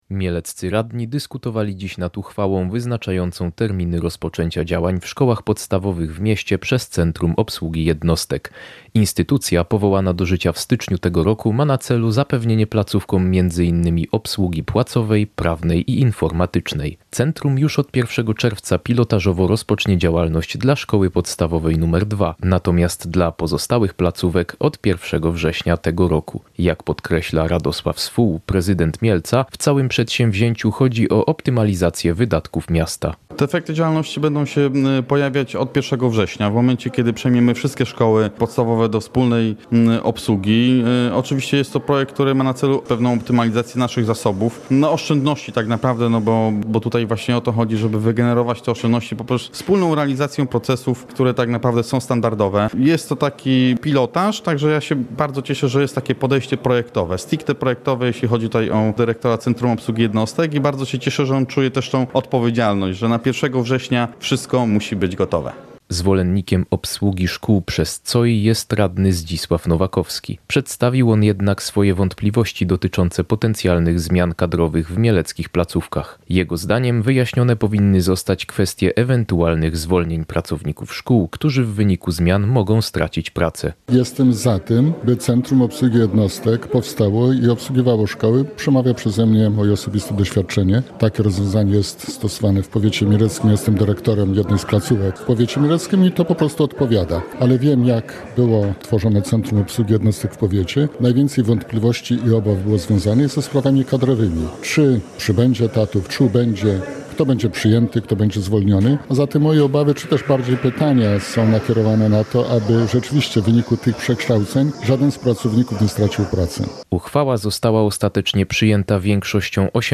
Chcemy w ten sposób wygenerować oszczędności – mówi Radosław Swół, prezydent Mielca.
Należy się upewnić, że w wyniku przekształceń nikt nie straci pracy – mówił radny Zdzisław Nowakowski.